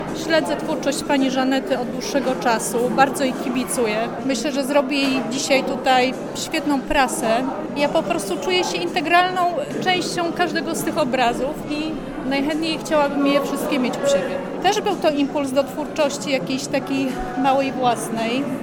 podkreśla jedna z uczestniczek wernisażu: